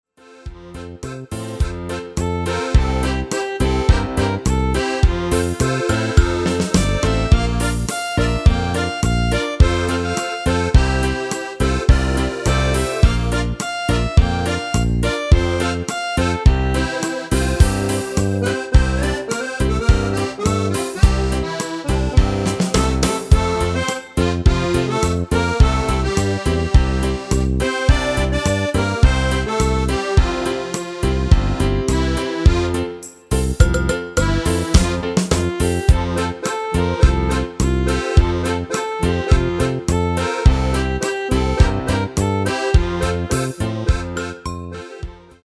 Demo/Koop midifile
Genre: Nederlands amusement / volks
Toonsoort: F
- Géén vocal harmony tracks